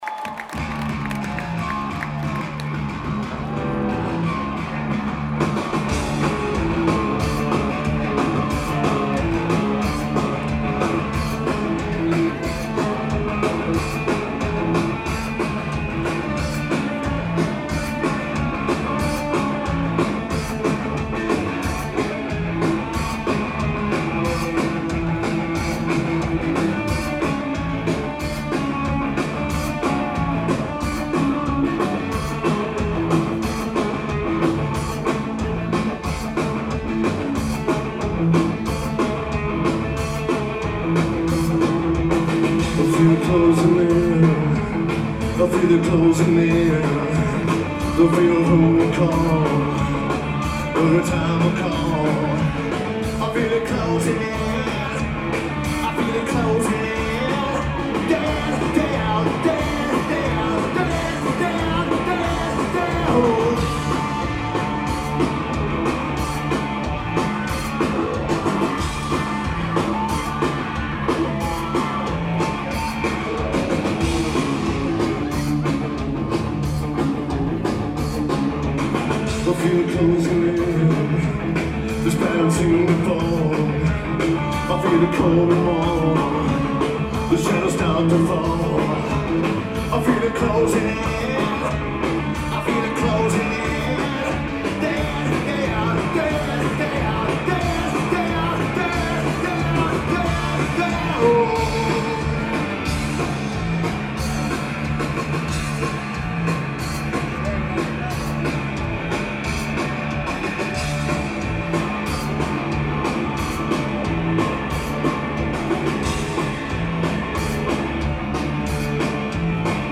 Las Vegas, NV United States
Lineage: Audio - AUD (SP-CMC-2 + Zoom H4n)